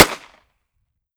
38 SPL Revolver - Gunshot A 004.wav